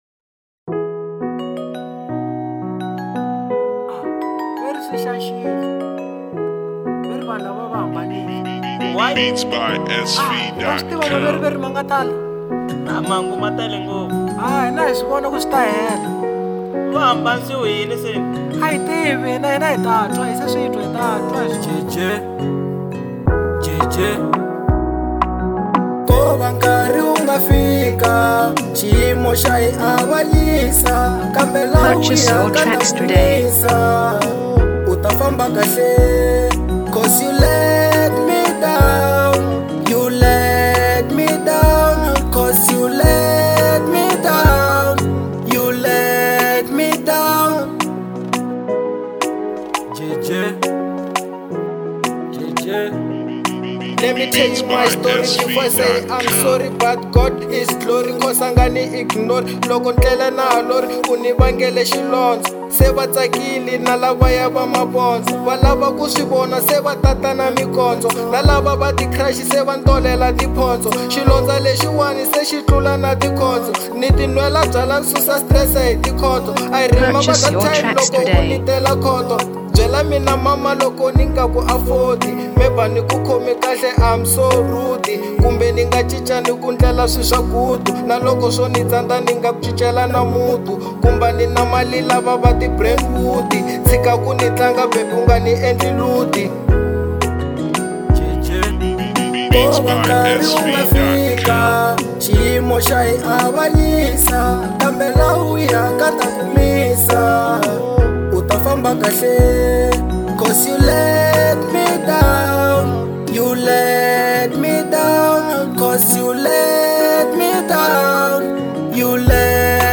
04:21 Genre : Hip Hop Size